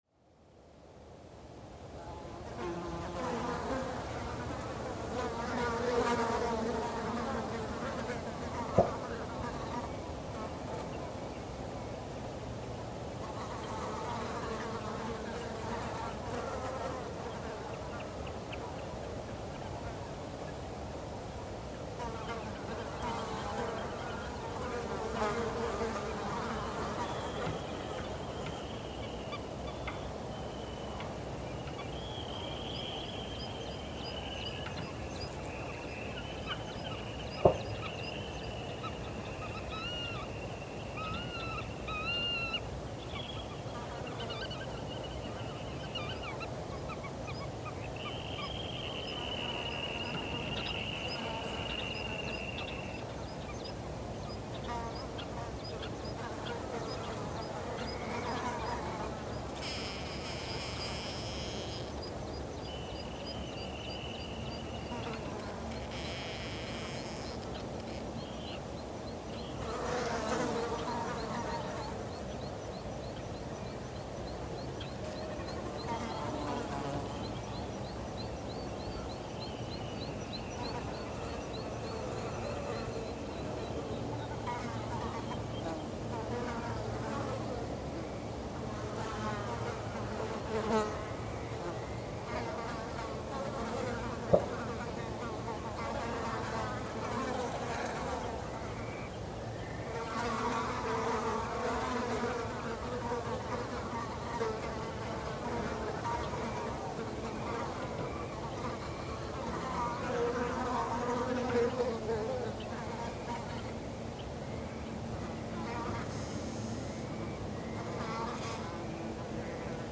Flugur undir húsvegg
En satt best að segja tókst mér það á dögunum undir húsvegg í friðlandinu í Flóa .
Flugnasuðið var svo gott sem það eina sem ég heyrði fyrir utan suðið í eigin höfði.
Vissulega heyrist mikið grunnsuð, ekki aðeins frá tækjum heldur líka frá flugvélum og bílaumferð norðan og sunnan við upptökustaðin. Þá barst líka talsverður “hávaði” frá öldurótinu við ósa Ölfusár. Fyrir utan flugnasuðið heyrist auðvitað líka í fuglum þó það komi mest á óvart hversu vel það heyrist þar sem þeir virtust flestir vera víðs fjarri á meðan á upptöku stóð. Önnur hljóð eru líklega þenslusmellir í húsinu, léttir smellir frá gluggaloku og einn þenlusmellur frá öðrum hljóðnemanum. Seinni hluta upptökunnar heyrist í bíl sem kemur að bílastæði friðlandsins og að lokum þegar fólkið úr þeim bíl kemur og stígur á pallinn sunnan við húsið. Tekið var upp þann 24. júní 2010 milli kl 17 og 18 á Korg MR1000 í 24bit/192Khz og Sennheiser ME62 hljóðnema. Þeim var vísað til norðurs með 90° horni, u.þ.b. 60cm frá húsveggnum.